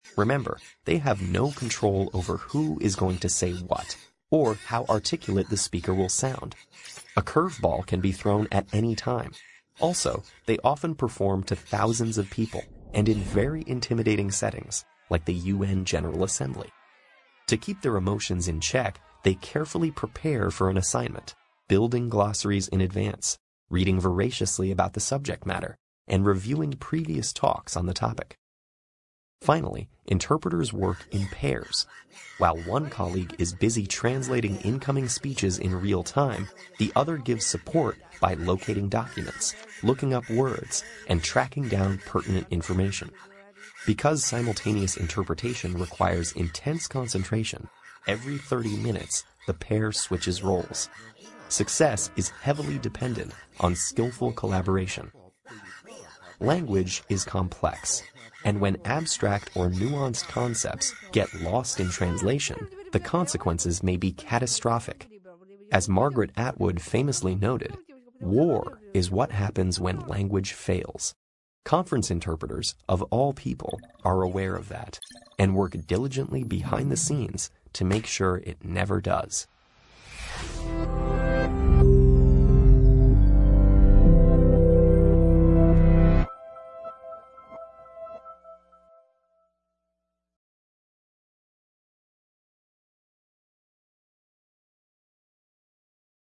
TED演讲:口译者如何同时玩转两种语言(3) 听力文件下载—在线英语听力室